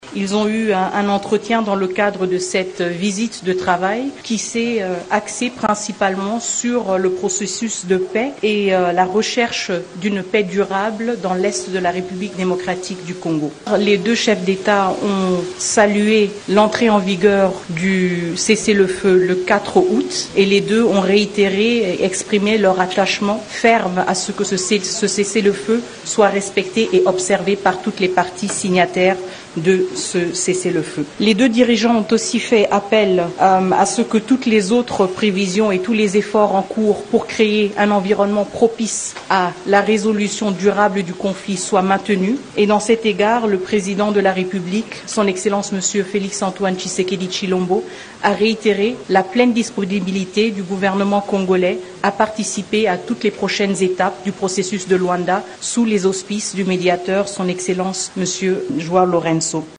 C’est ce qu’a affirmé la ministre congolaise des Affaires étrangères Thérèse Kayikwamba Wagner, qui a lu le compte rendu de la rencontre entre les Présidents de la RDC Félix-Antoine Tshisekedi et de l’Angola, João Lourenço.